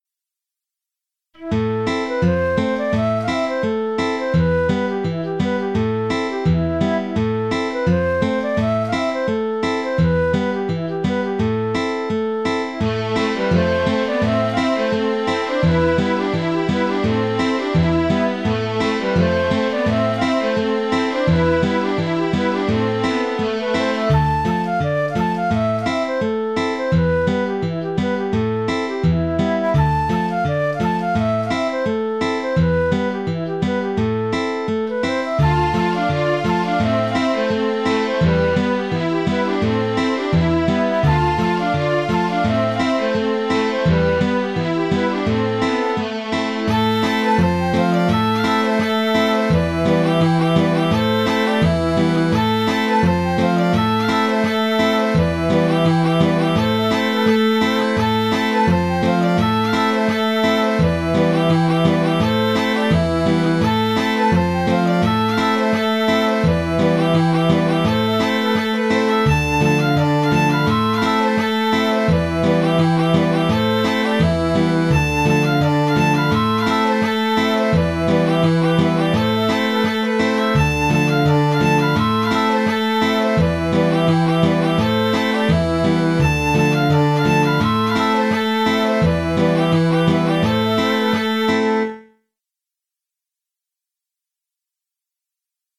Je propose deux contre-chants à alterner.